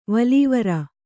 ♪ valīvara